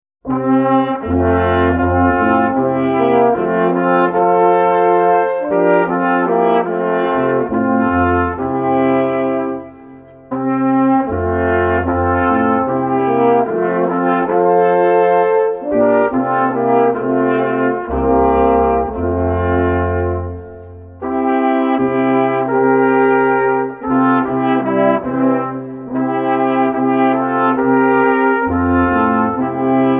Genre: Christmas
Traditional Bavarian and Tyrolean Christmas favorites